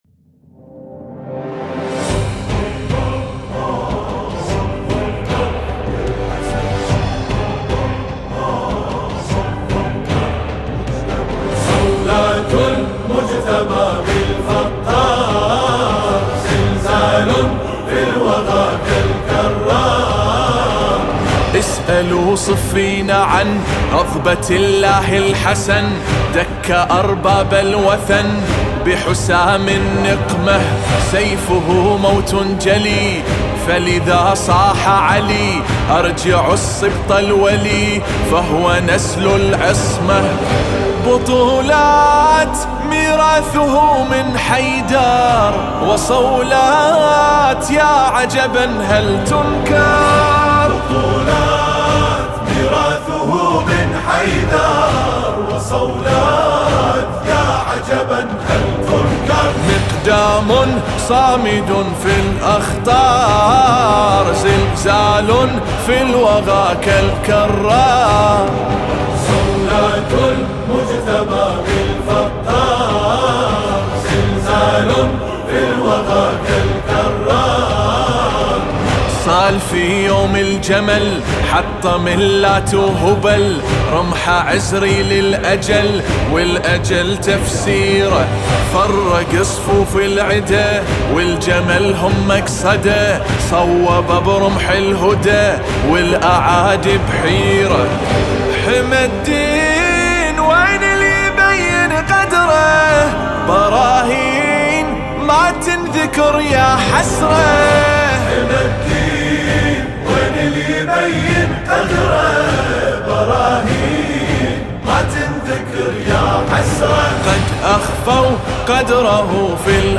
لطمية, المجتبى, الامام, الحزن, اقوى, تسمعها, حماسية, جديدة, راح, زلزال, صولات, كالكرار